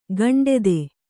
♪ gaṇḍede